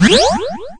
Heal6.ogg